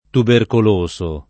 tubercoloso [ tuberkol 1S o ]